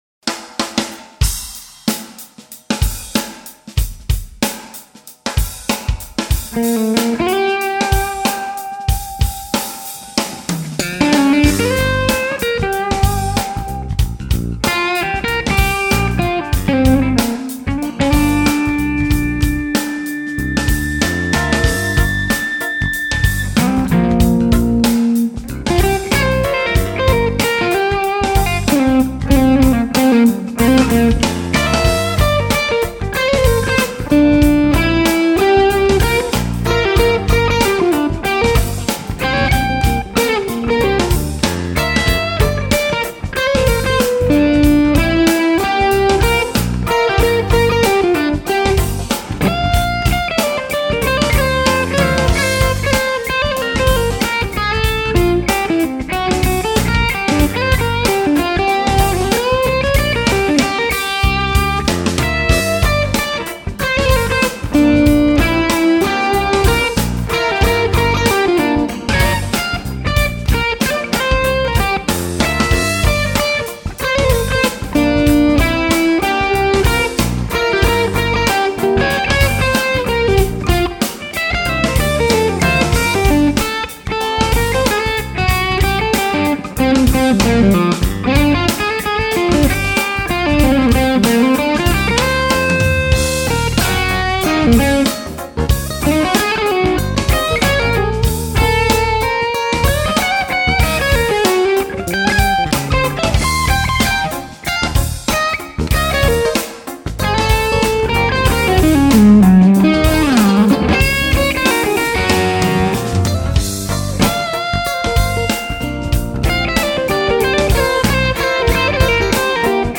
Phillips 7581A tubes and a more optimized OD1 cap
I think the Phillips clip is tighter and nicer.